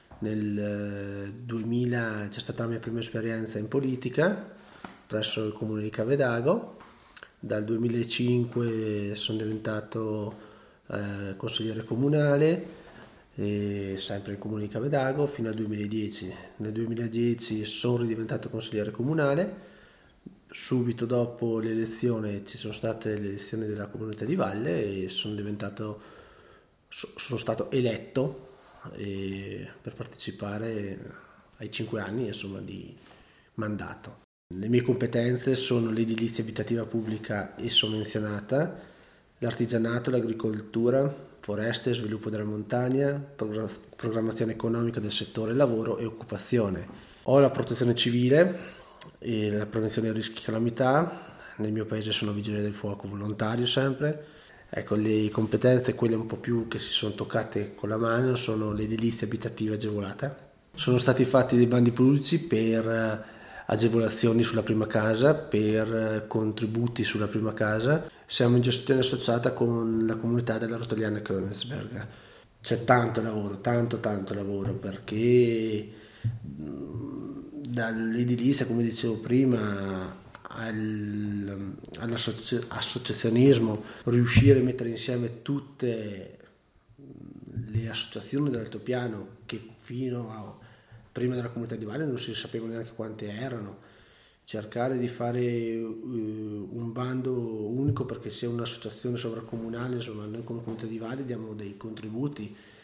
Presentazione audio dell'assessore Daniele Daldoss